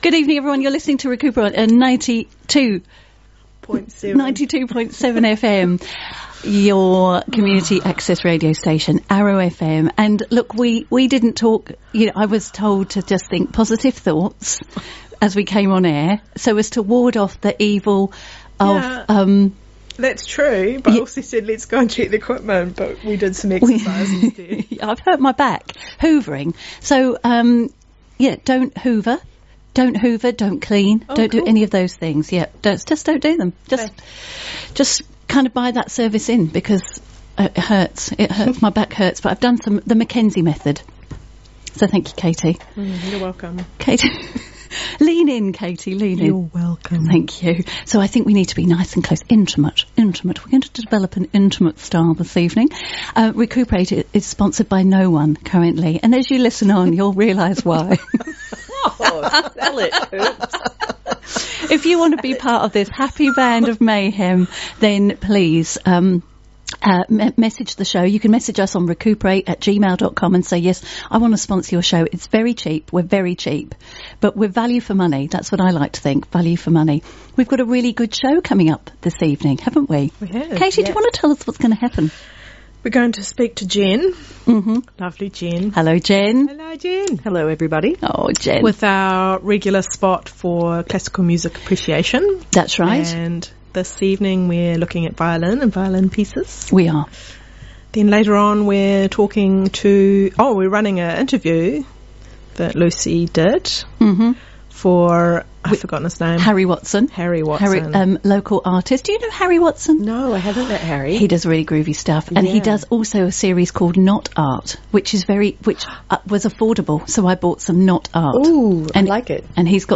pre-recorded interview